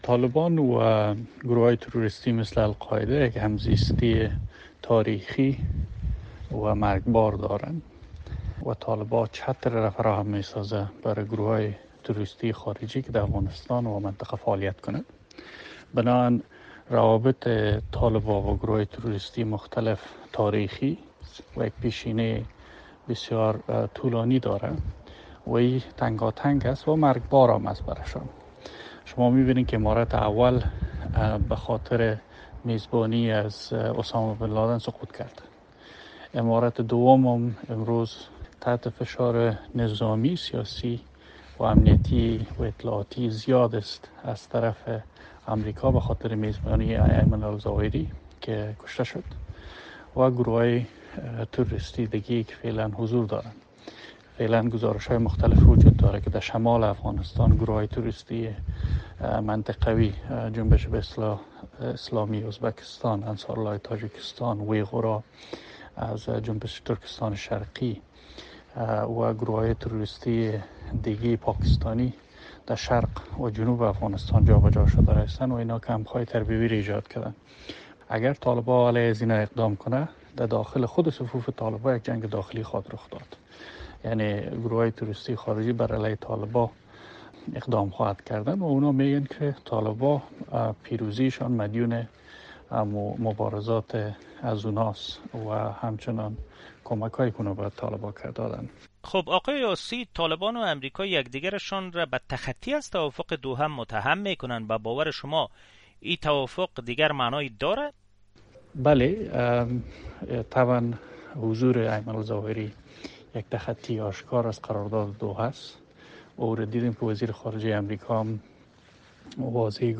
مصاحبه - صدا
تمیم عاصی معاون پیشین وزارت دفاع افغانستان